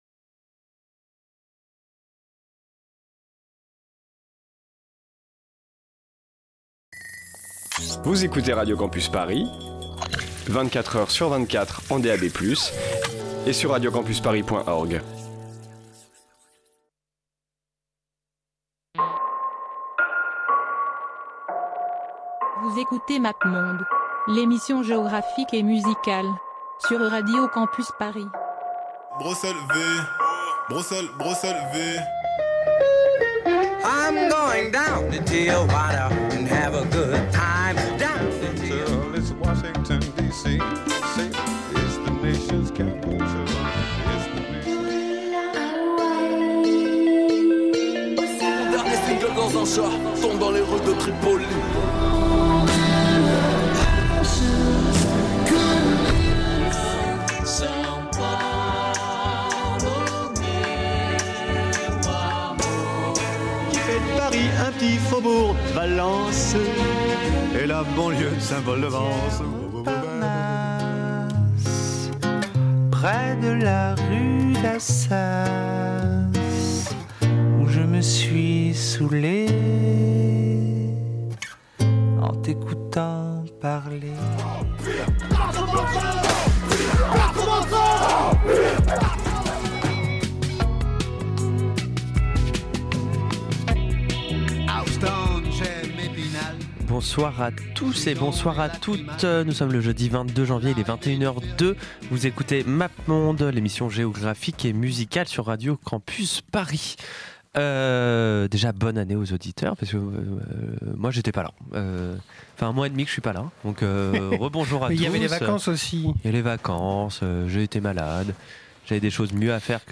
Musique : Nigéria vs. Maroc